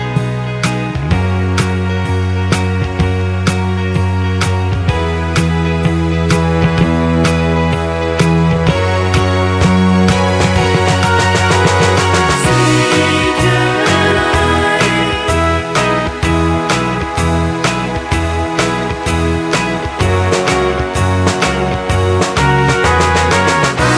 Minus Guitars Pop (1970s) 3:42 Buy £1.50